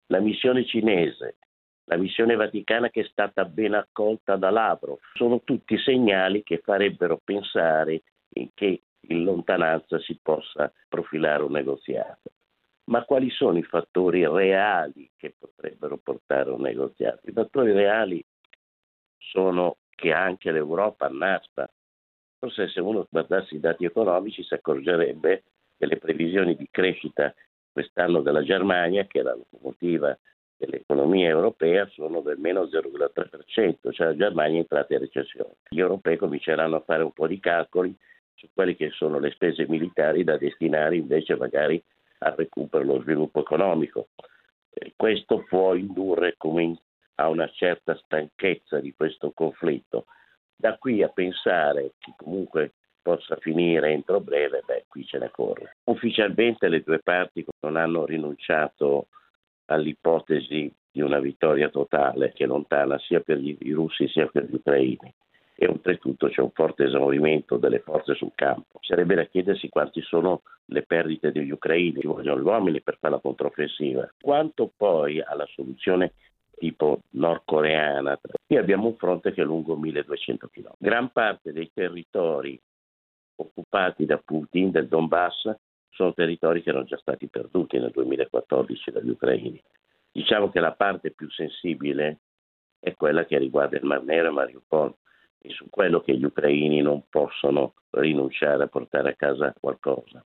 Sulla percorribilità di una strada diplomatica, abbiamo sentito